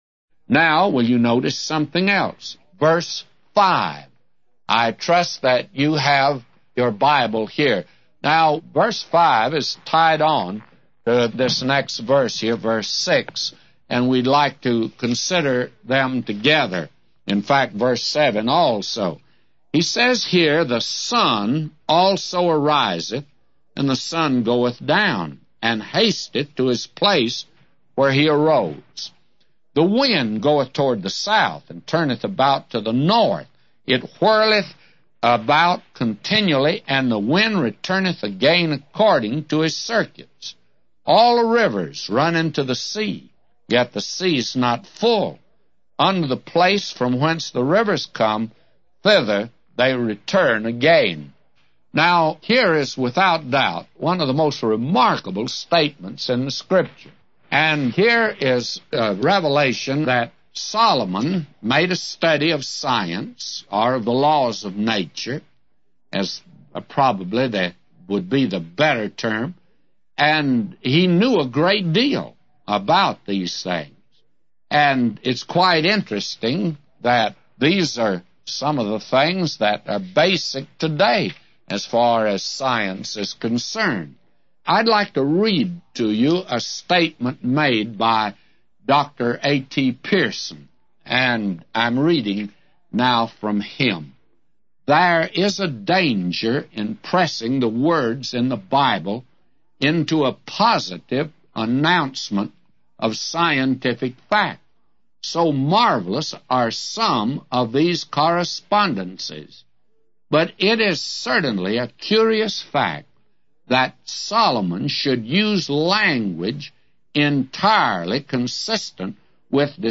A Commentary By J Vernon MCgee For Ecclesiastes 1:5-999